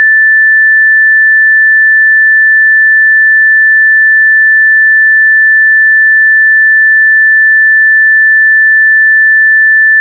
A6a‴세 줄1760Hz
1760 사인파